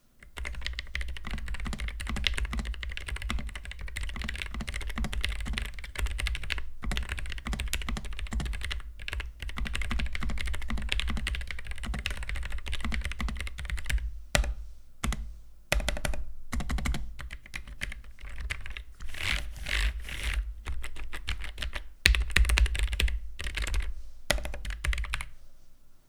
keychronk3max.wav